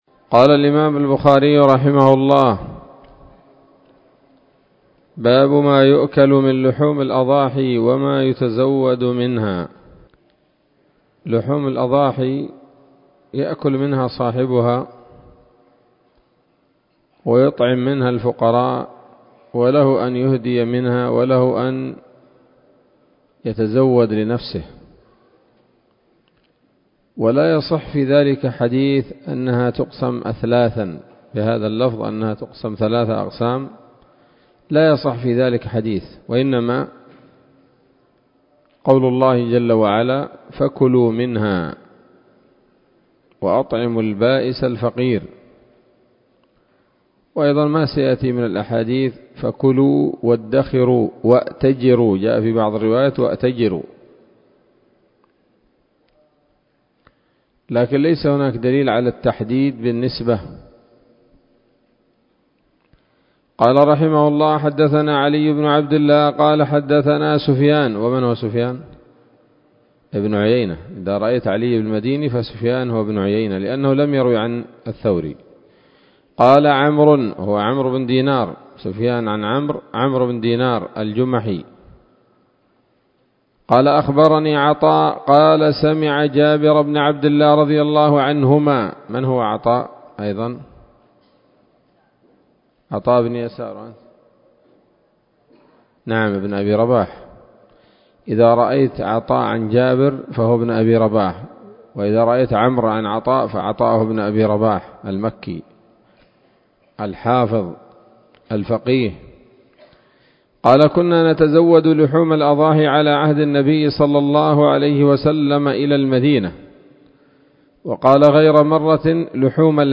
الدرس الثالث عشر وهو الأخير من كتاب الأضاحي من صحيح الإمام البخاري